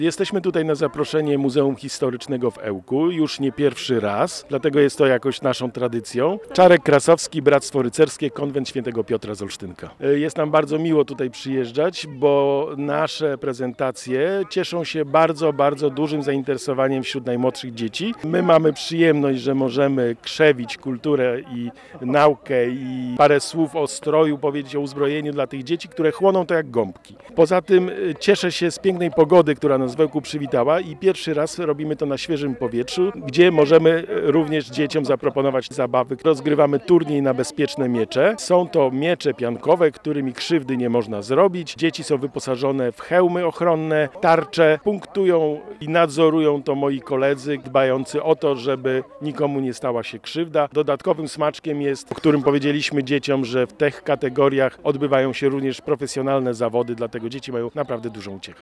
O tradycjach i zwyczajach rycerskich opowiadali przedstawiciele bractwa rycerskiego konwent św. Piotra z Olsztynka. Tym razem spotkanie organizowane przez Muzeum Historyczne w Ełku odbyło się w plenerze.